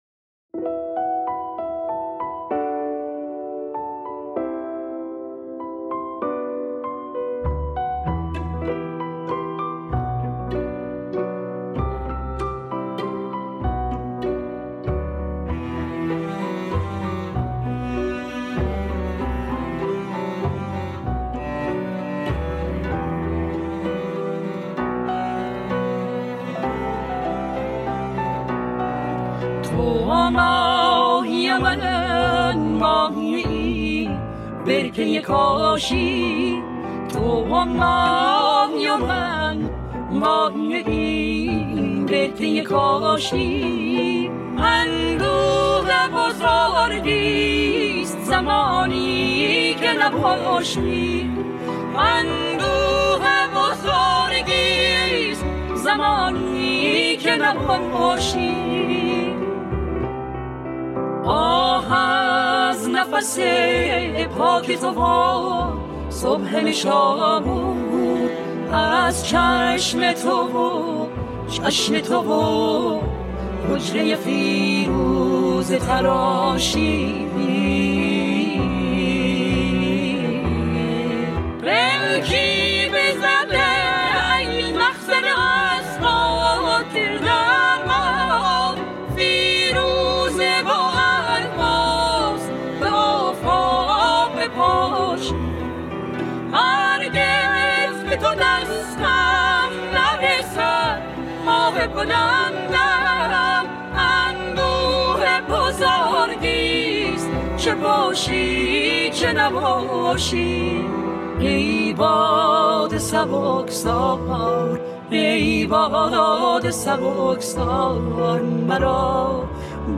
اهنگ کردی
اهنگ محلی